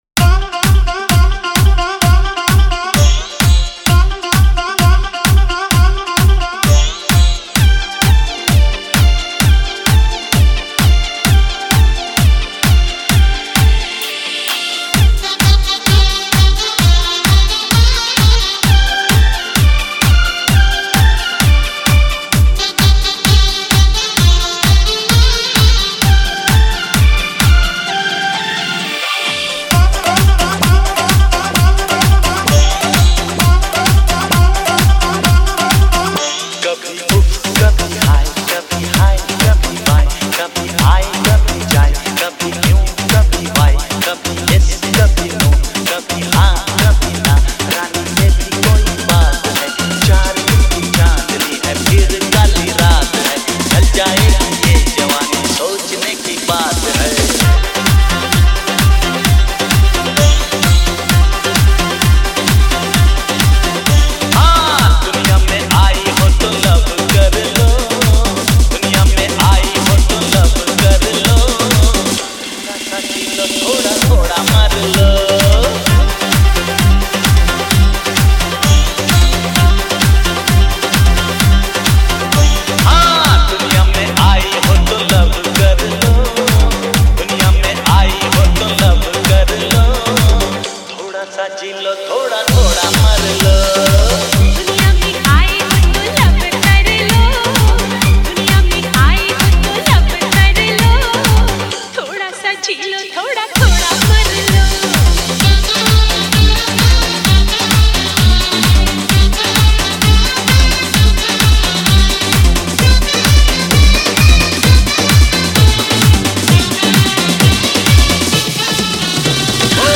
DJ Remix Songs